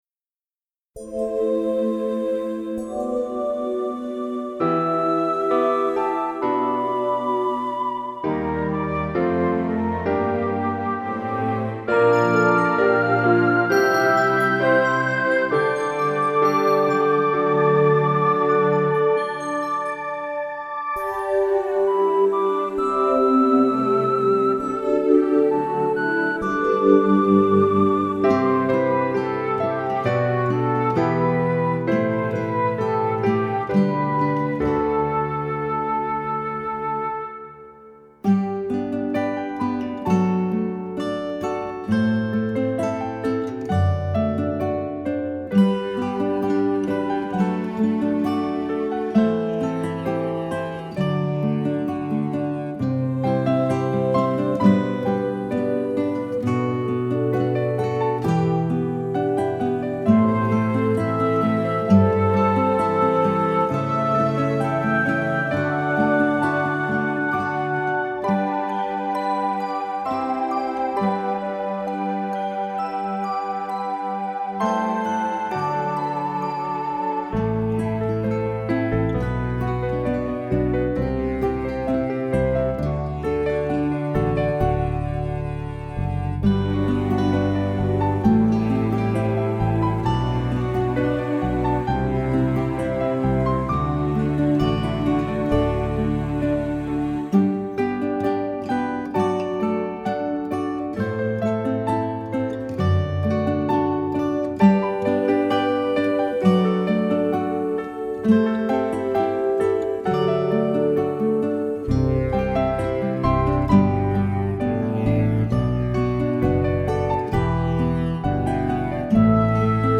Below are some recent guitar mixes I’ve done:
no-words-2-guitar-mix-7th-fret-revised.mp3